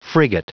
Prononciation du mot frigate en anglais (fichier audio)
frigate.wav